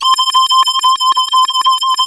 OSCAR 14 C3.wav